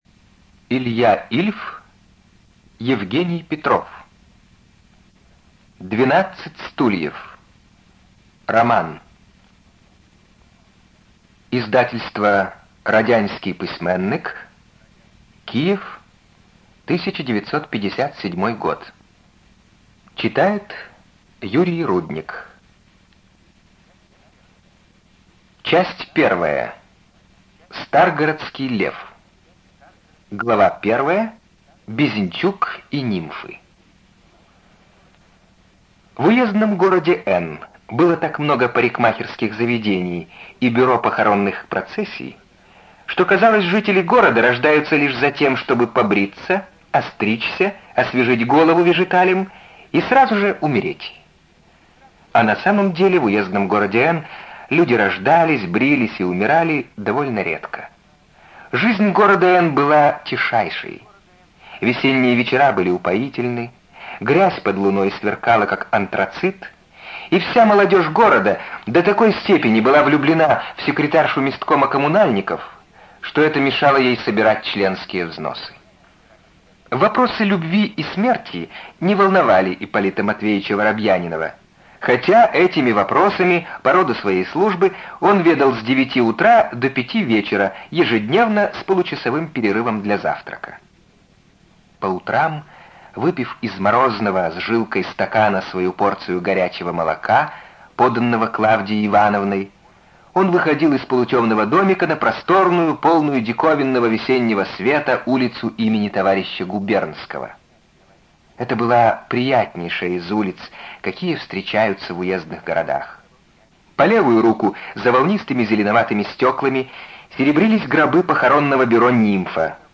ЖанрЮмор и сатира
Студия звукозаписиРеспубликанский дом звукозаписи и печати УТОС